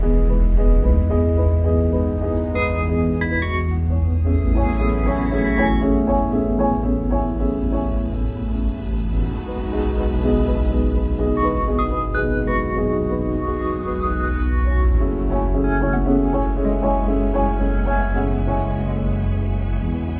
asmr after tennis pink self sound effects free download